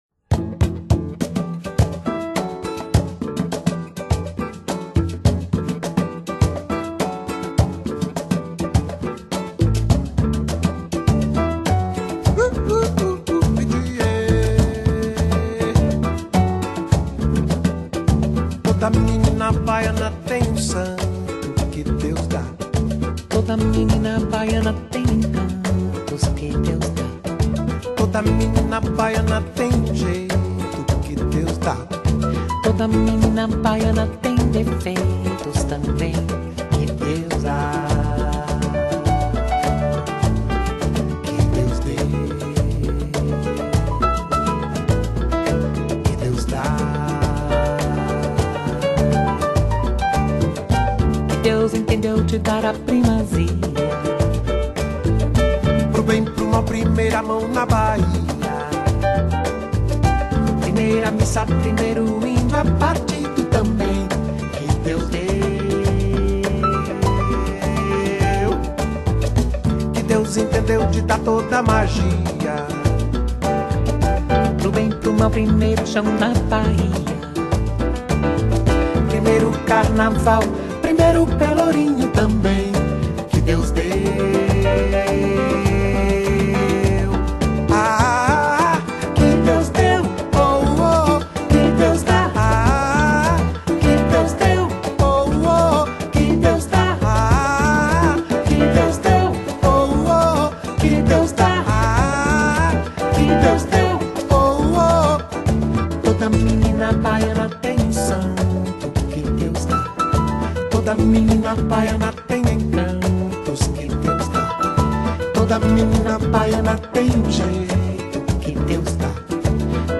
慵懶富磁性的聲線、細膩咀嚼歌詞意境的演唱方式，自在感與歌曲詮釋風格，在在展現了Bossa Nova的神韻精髓。
遙望異國情懷，Bossa Nova與爵士鋼琴彈奏、演唱，像來自大海的呼喚，也像夏日微風中的呢喃輕唱。